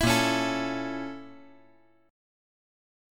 BbmM7b5 Chord
Listen to BbmM7b5 strummed